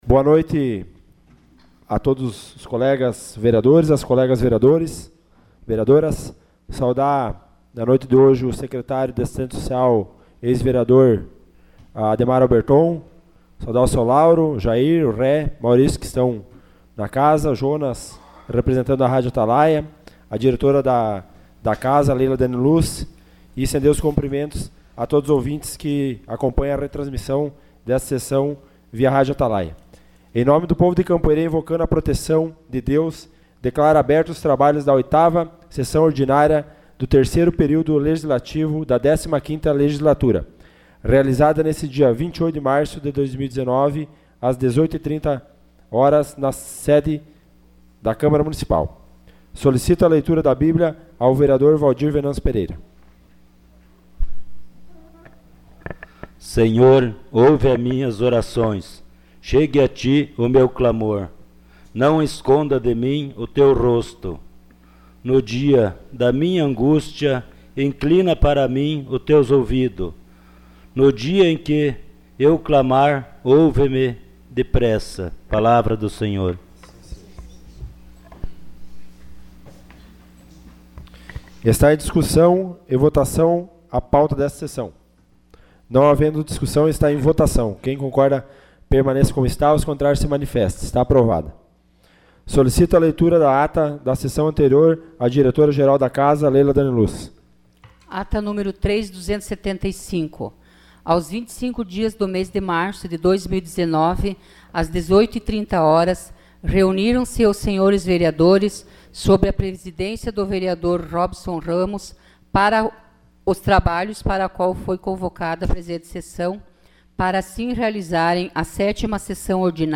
Sessão Ordinária 28 de março de 2019.